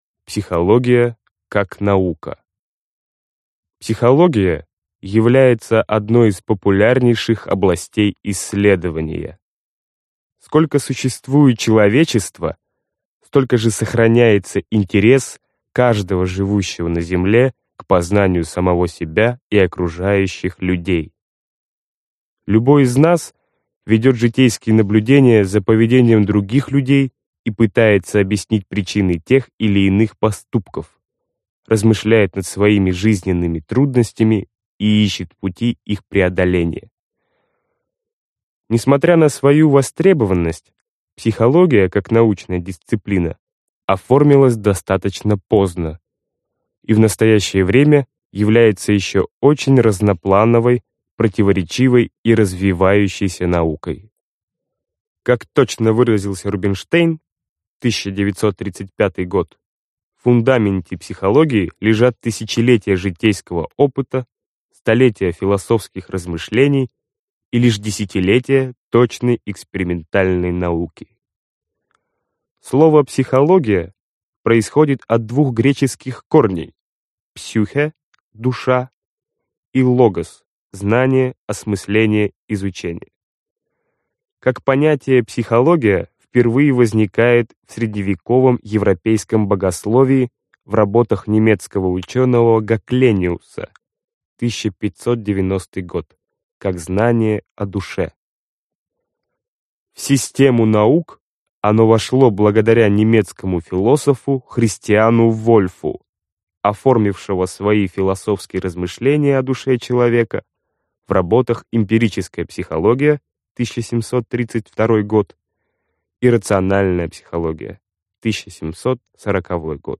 Аудиокнига Лекции по психологии | Библиотека аудиокниг